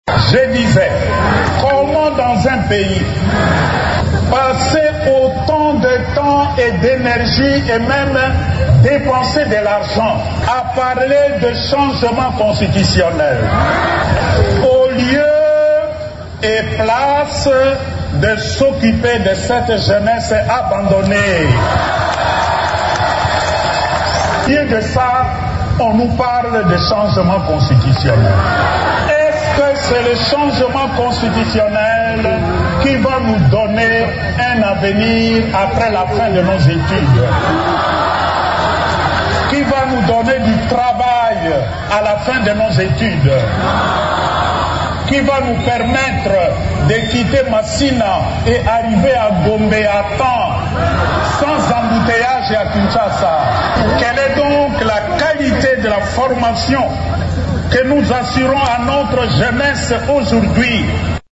Le cardinal Fridolin Ambongo a fustigé la révision constitutionnelle, dimanche 24 novembre à Kinshasa, au cours de son homélie à l’occasion des journées diocésaines des jeunes catholiques.